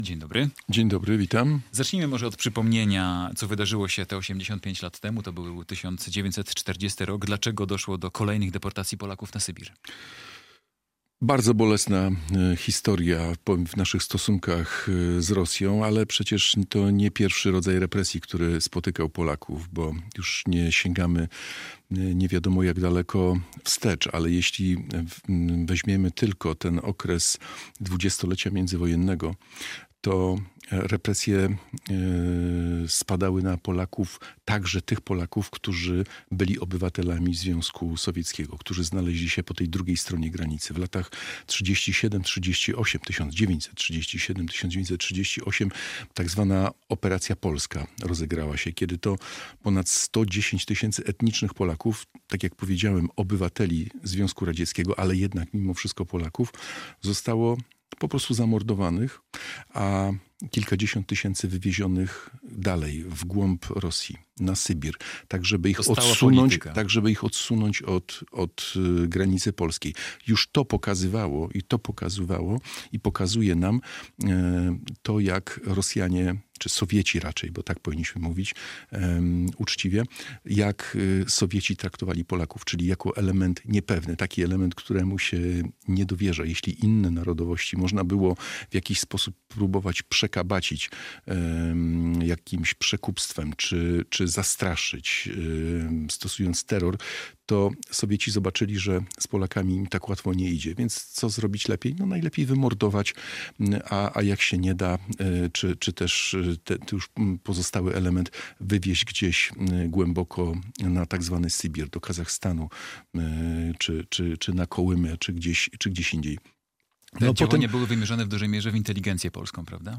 rozmawia